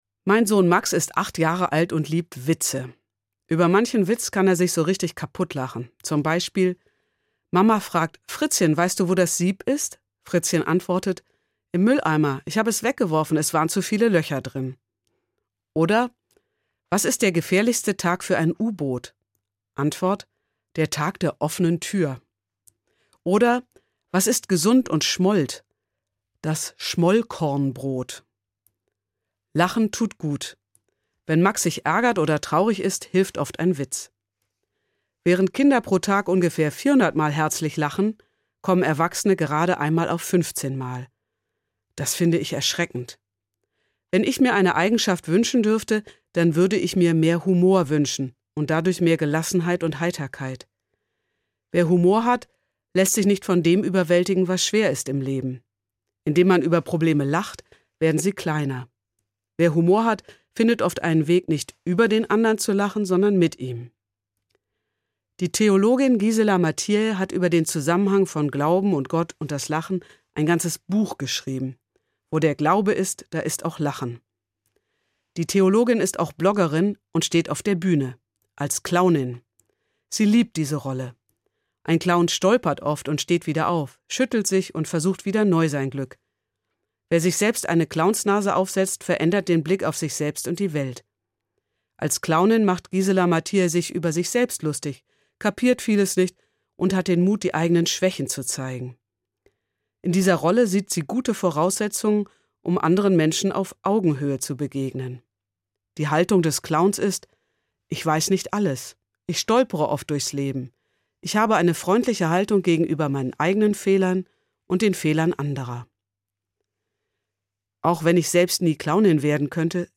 Evangelische Pfarrerin, Frankfurt